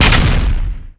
explode2.wav